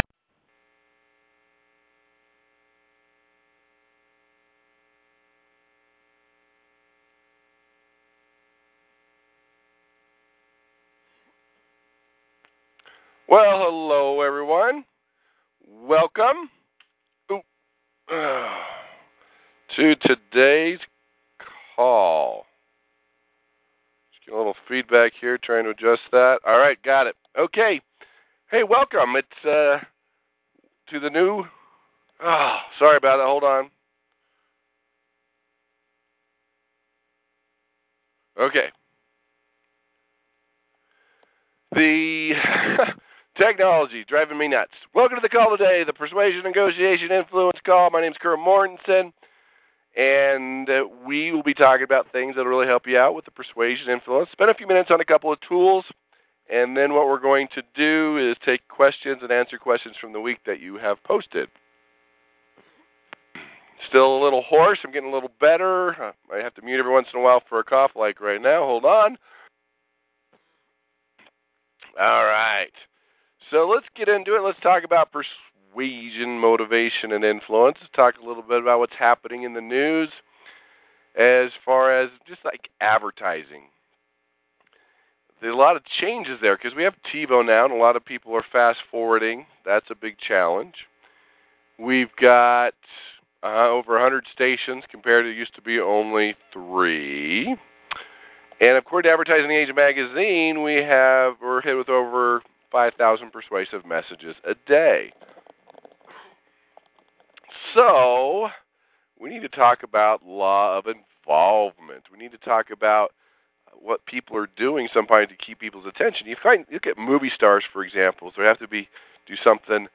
‹ Maximize influence intro Meta programs › Posted in Conference Calls